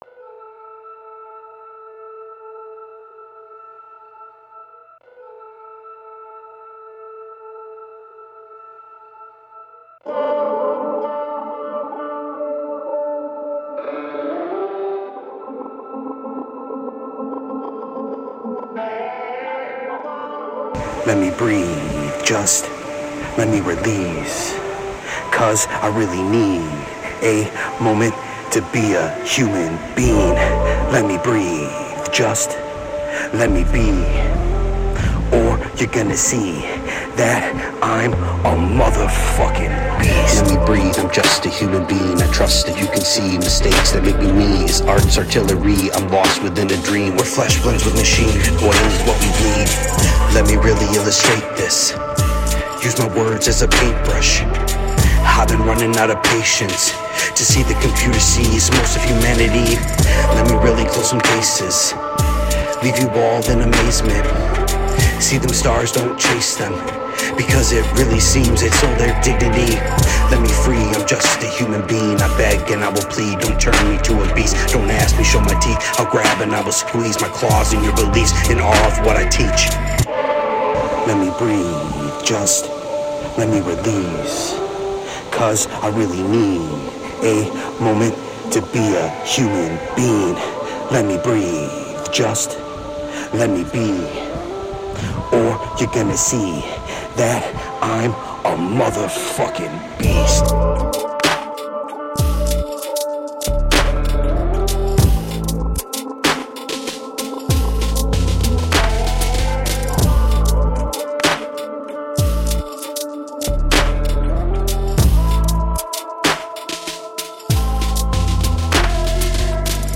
Not mixed or mastered( recorded on my phone)